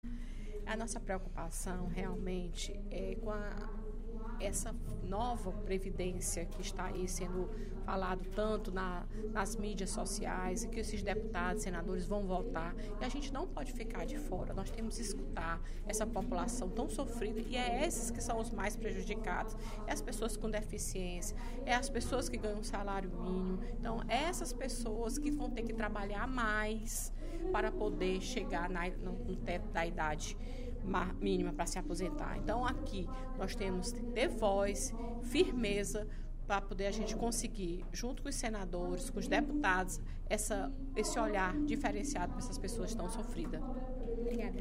A deputada Fernanda Pessoa (PR) destacou, durante o primeiro expediente da sessão plenária desta sexta-feira (17/02), a proposta de reforma da Previdência, do Governo Federal. Segundo a parlamentar, a proposta vai acarretar graves mudanças, prejudicando os cidadãos mais fracos, além dos trabalhadores que contribuíram com o INSS.